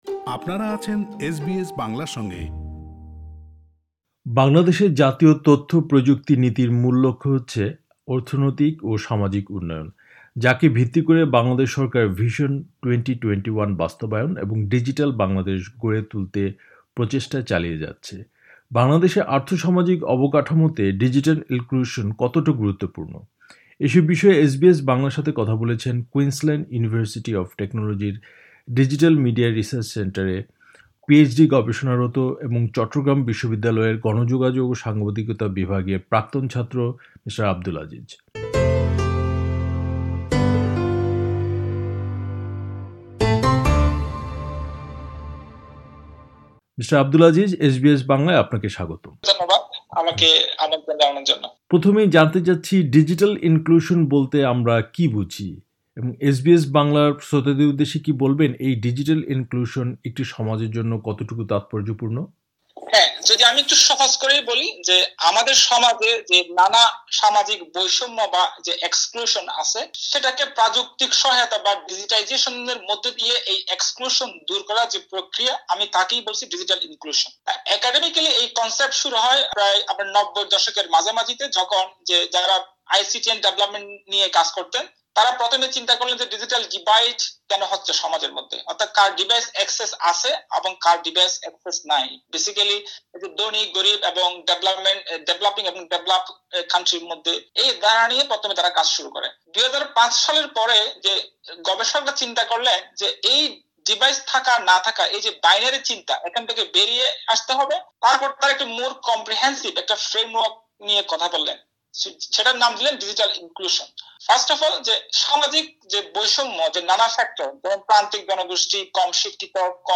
এসব বিষয়ে এসবিএস বাংলার সাথে কথা বলেছেন